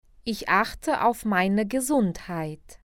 Dicas de pronúncia:
[ge] tem som de gue
[s] tem som de z
[h] som deve ser feito na garganta
[ei] som de ai